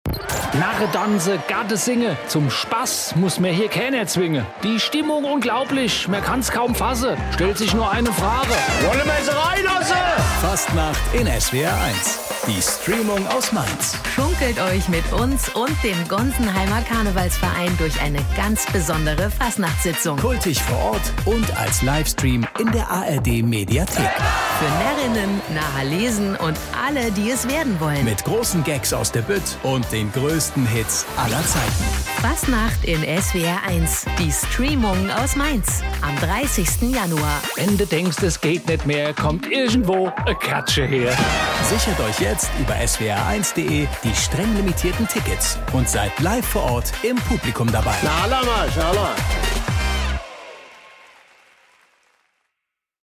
streamung-radiotrailer.mp3